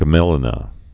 (gmĕlĭ-nə, gmālĭ-)